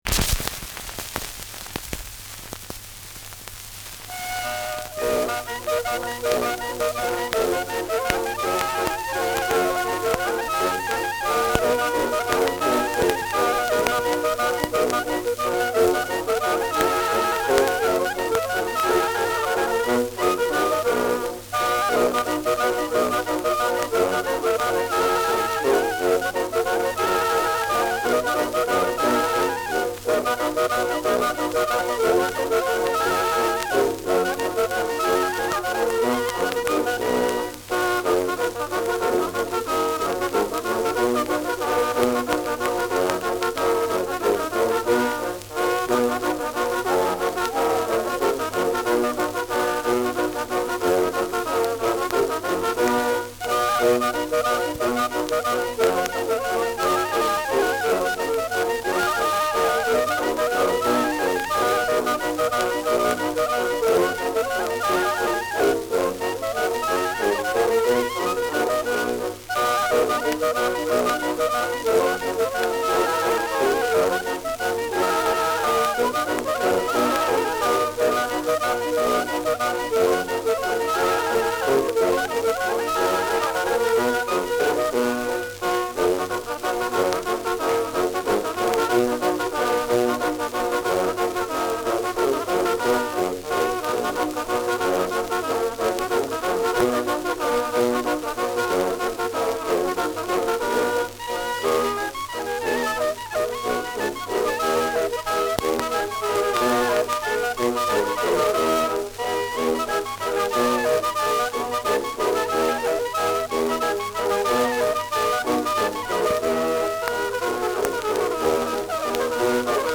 Schellackplatte
Abgespielt : Zu Beginn stärkeres Knacken : Durchgehend leichtes Knacken
[Nürnberg?] (Aufnahmeort)